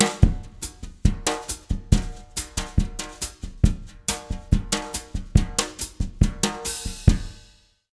140BOSSA02-R.wav